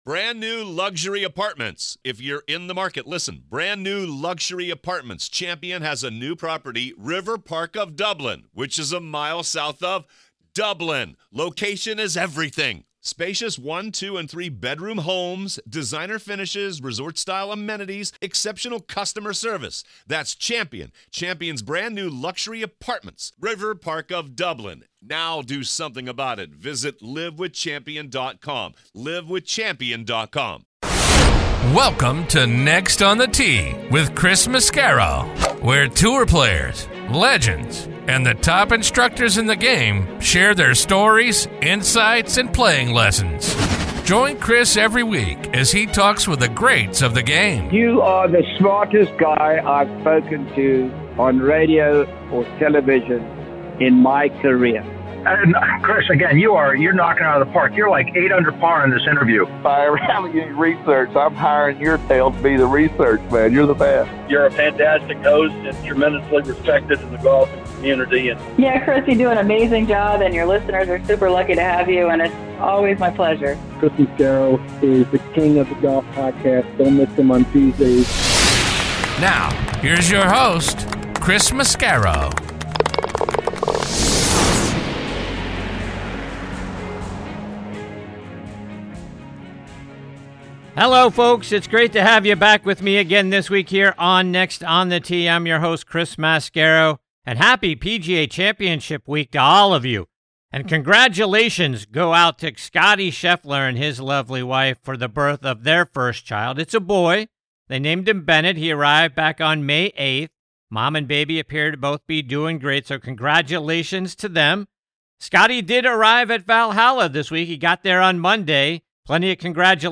In Part 1 of Episode 28, I'm thrilled to be joined by two incredible guys: former Pirates and Red Sox outfielder Adam Hyzdu, and 5-time winner on Tour, now outstanding broadcaster Jim Gallagher Jr.